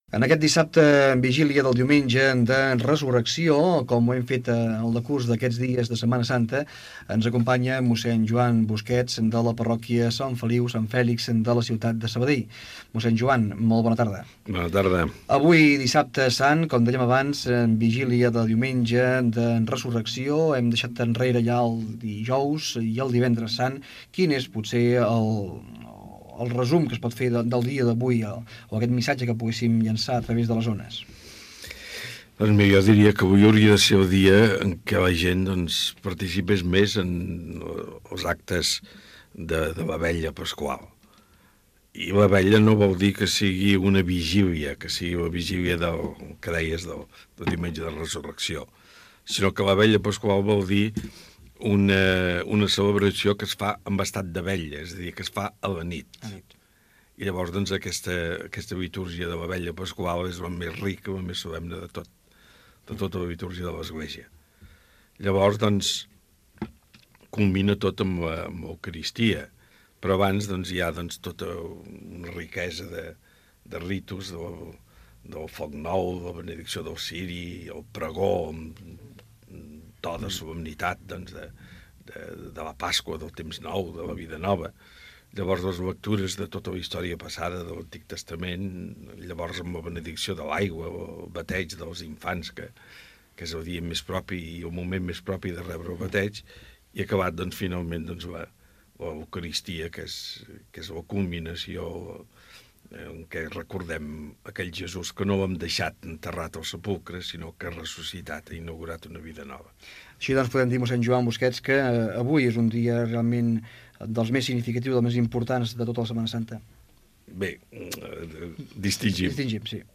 Religió